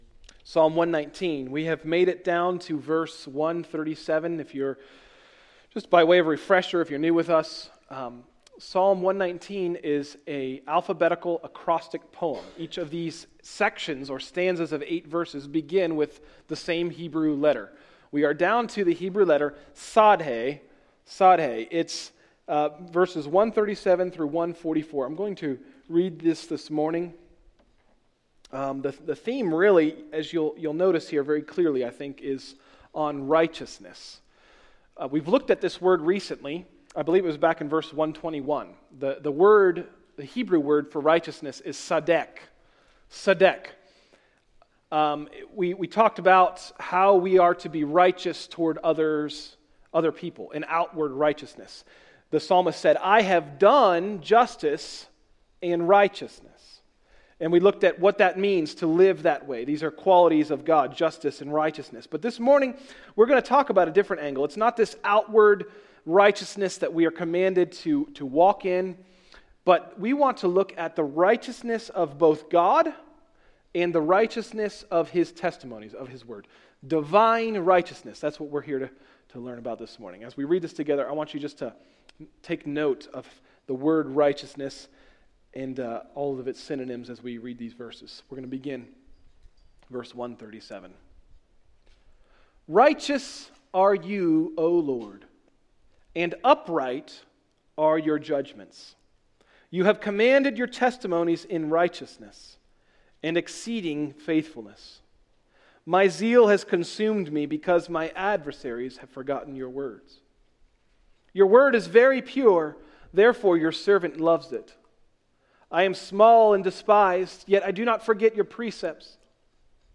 Message: “Tsadhe: Psalm 119” – Tried Stone Christian Center